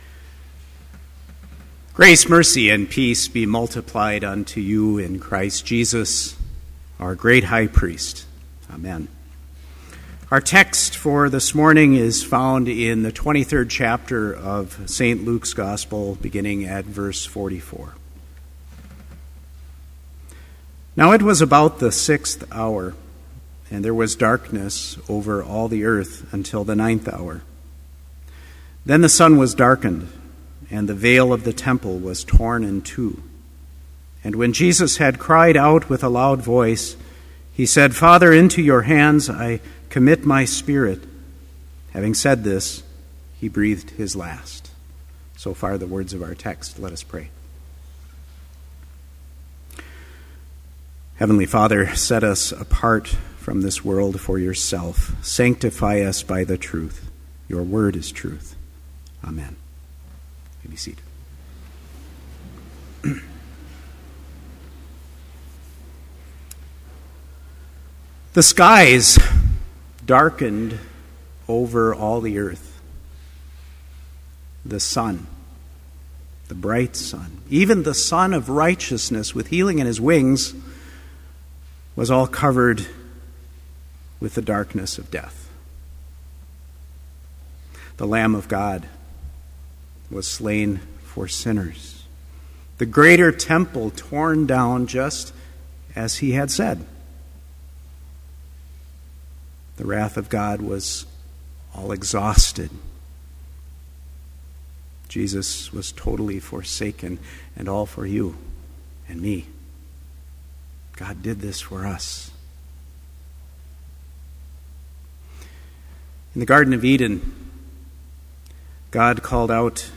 Complete Service
• Prelude
• Homily
This Chapel Service was held in Trinity Chapel at Bethany Lutheran College on Tuesday, March 19, 2013, at 10 a.m. Page and hymn numbers are from the Evangelical Lutheran Hymnary.